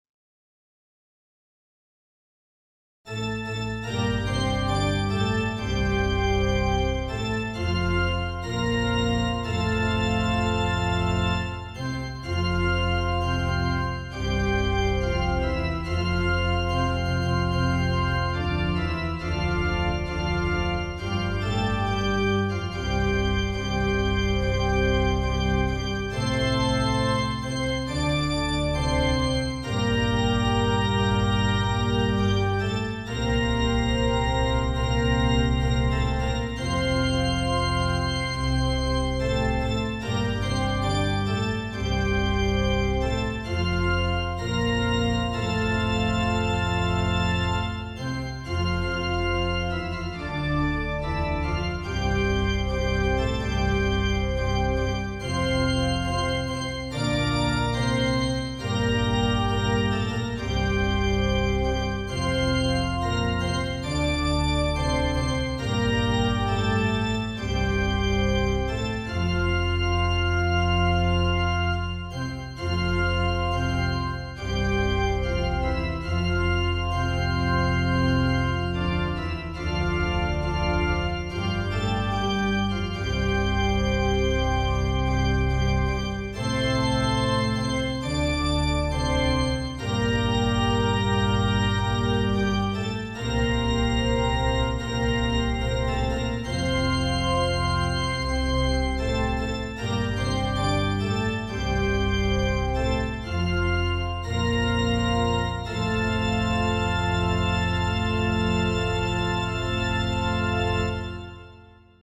Pambungad na Awit